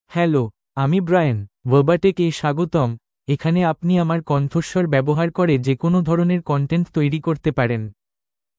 MaleBengali (India)
Brian is a male AI voice for Bengali (India).
Voice sample
Listen to Brian's male Bengali voice.
Male